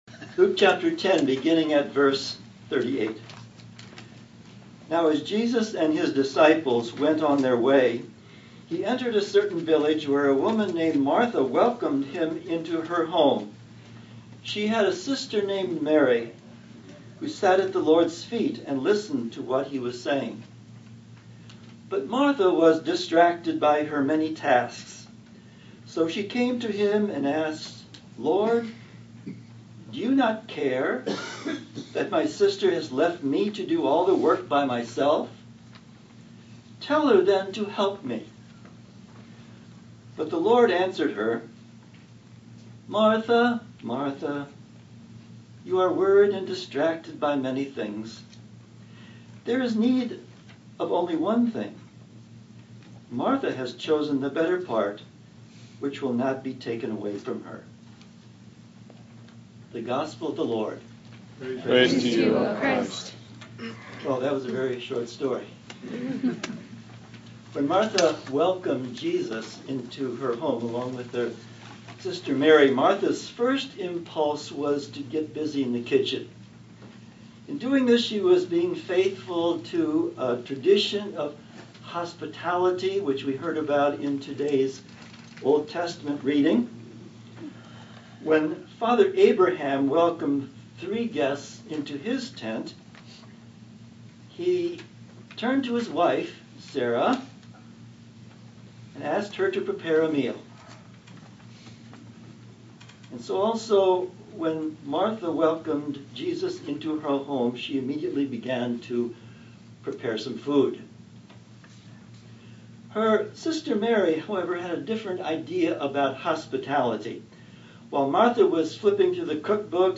Sermons and Anthems | The Second Reformed Church of Hackensack